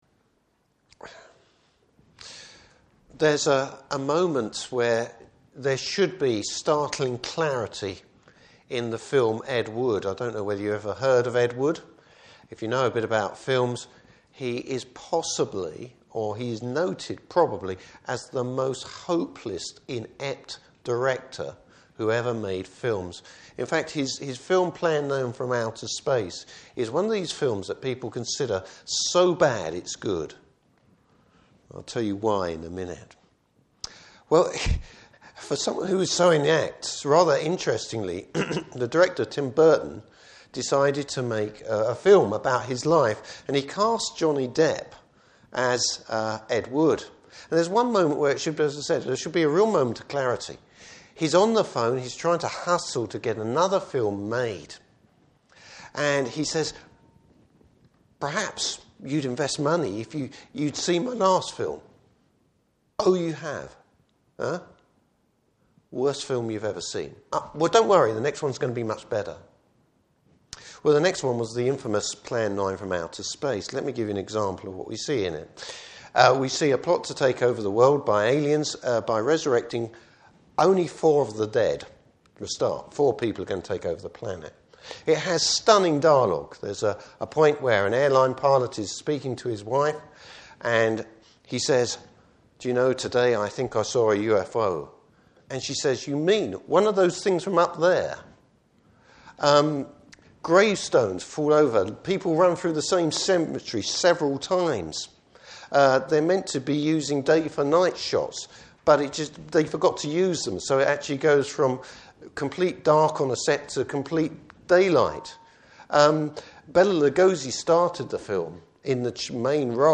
Service Type: Evening Service Bible Text: Revelation 3:14-22.